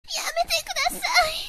sounds / mob / villager / hit4.ogg